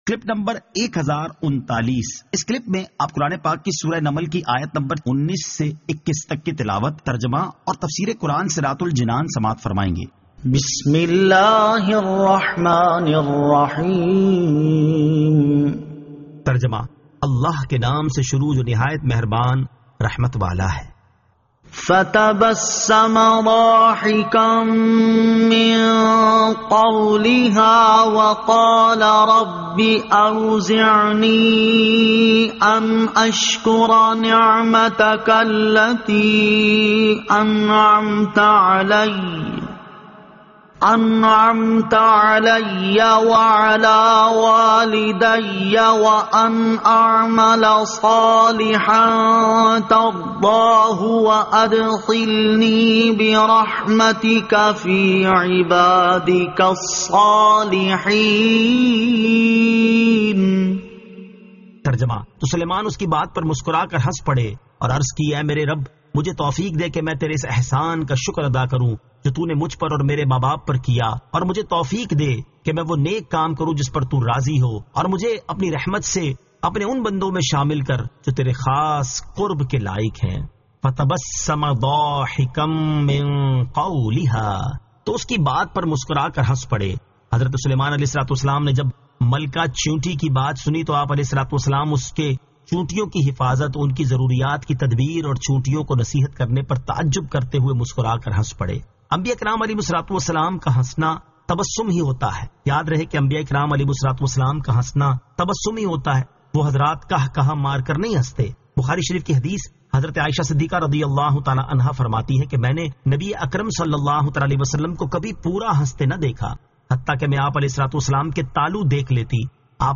Surah An-Naml 19 To 21 Tilawat , Tarjama , Tafseer